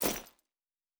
Fantasy Interface Sounds
Bag 13.wav